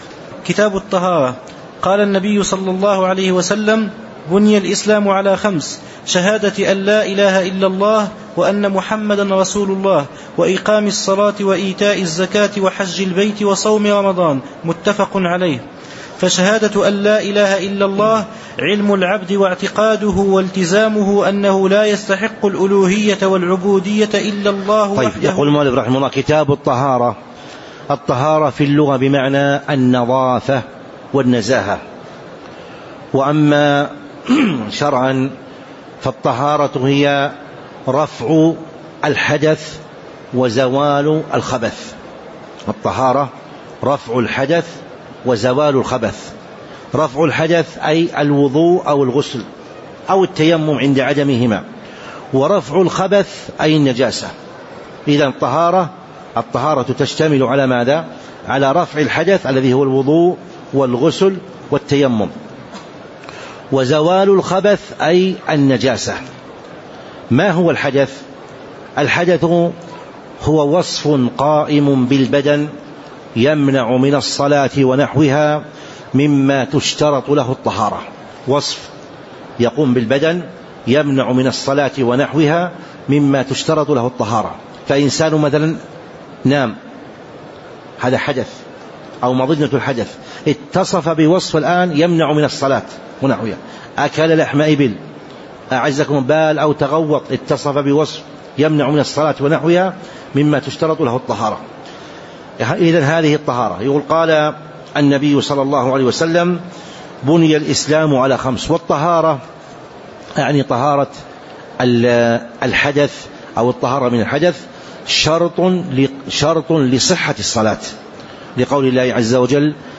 تاريخ النشر ٤ صفر ١٤٤٥ هـ المكان: المسجد النبوي الشيخ